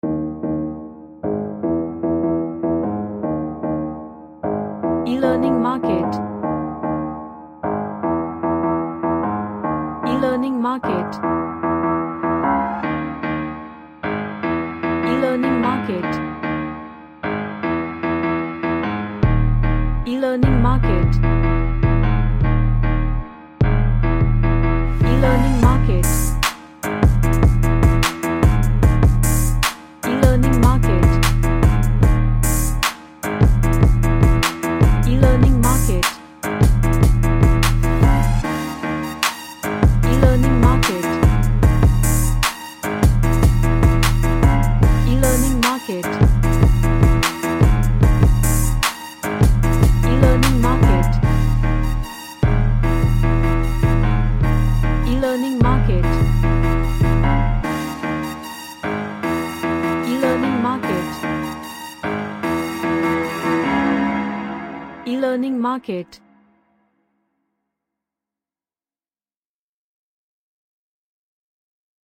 A bizarre track with gangstar vibe
Strange / Bizarre